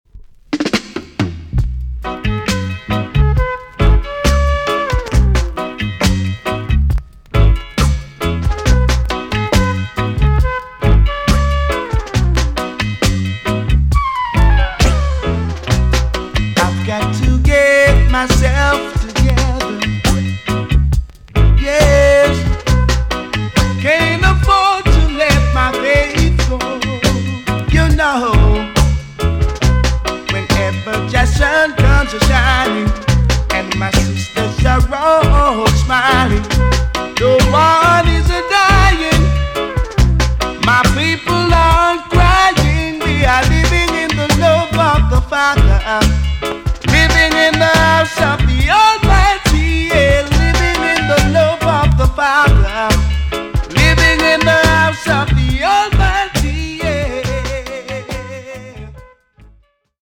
TOP >DISCO45 >80'S 90'S DANCEHALL
EX 音はキレイです。